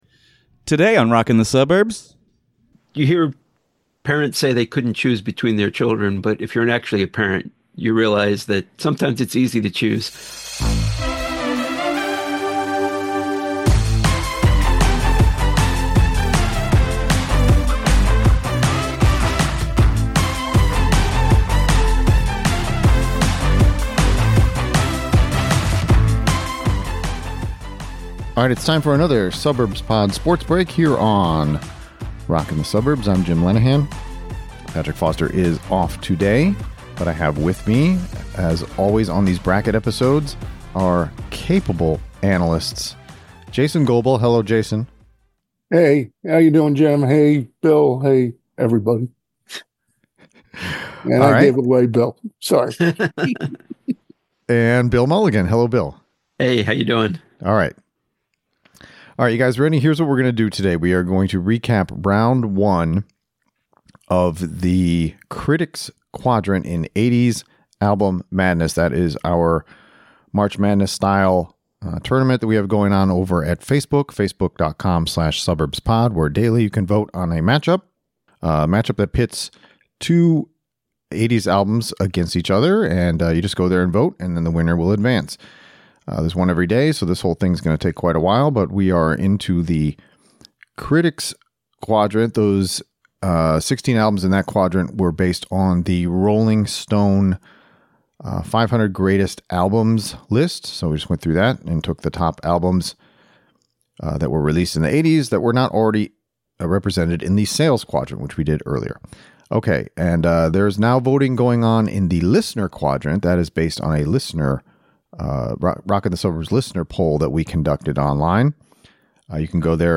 Come join two (not so) ordinary family guys in the basement (or on the deck) as they talk about their lifelong obsessions with music.